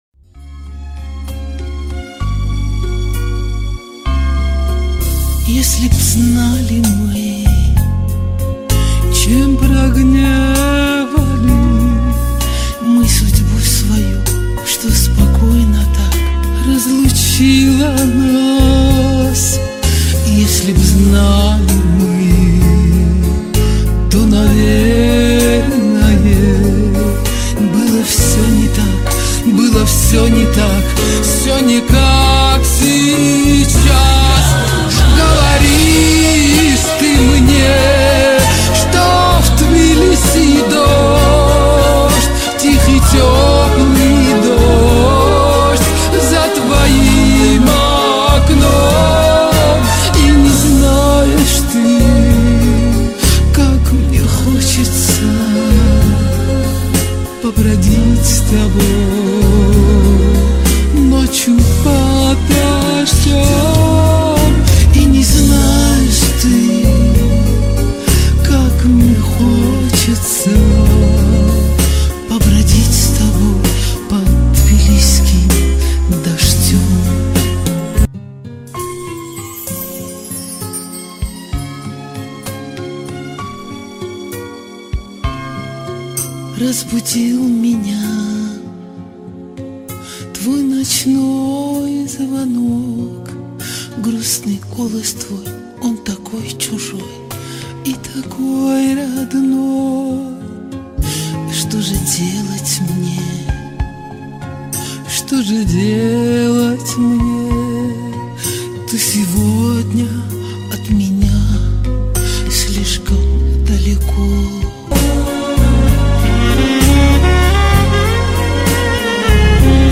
Кстати, у меня рип из фильма, а может есть студийная запись?